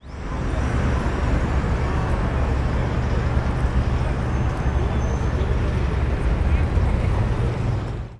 City-Noise-Ambient-5.wav